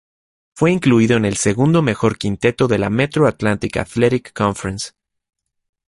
quin‧te‧to
/kinˈteto/